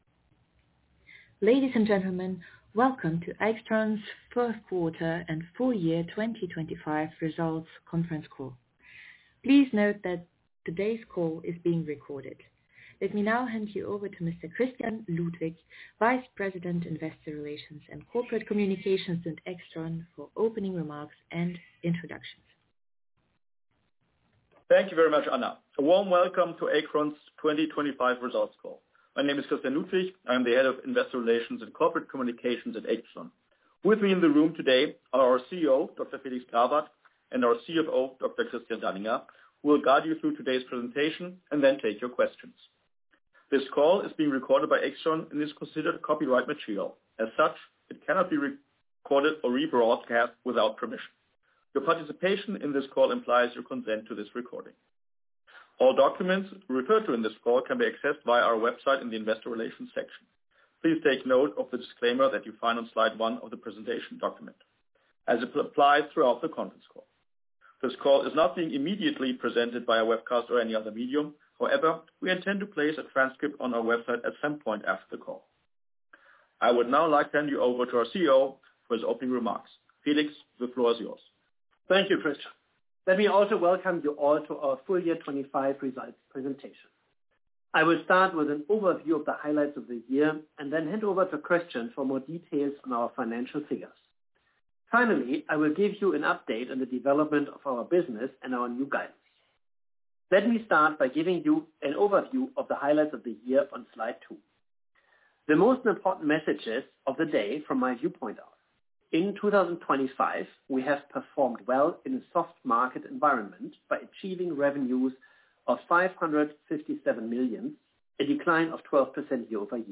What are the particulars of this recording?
In conjunction with the release of the H1/2025 Results AIXTRON held a conference call (in English) for analysts and investors on Thursday, July 31, 2025 at 3:00 pm (CEST), 06:00 am (PDT), 09:00 am (EDT).